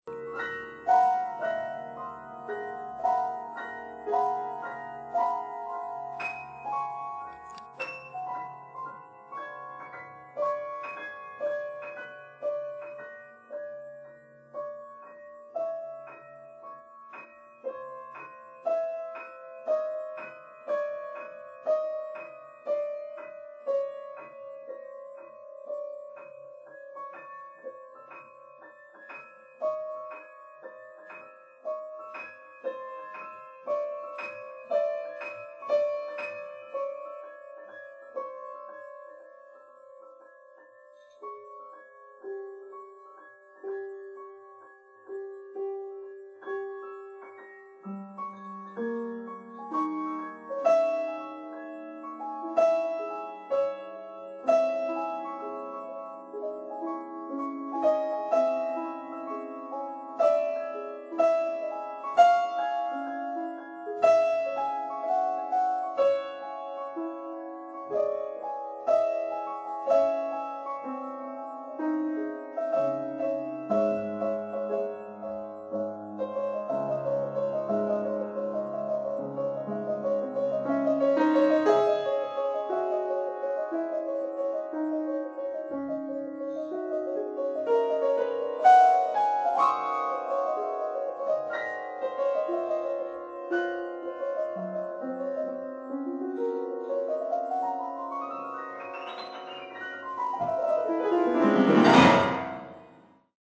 It’s a improv or what?
hm… it’s full of hand positions, so could be able to remember it only by putting your hands at the piano.
There’s lot of F,F#,G#,A# like clusters… and a C#minor constant arpeggiato like G#C#E
Also a C,C#,D#,E,D#,C#… motif…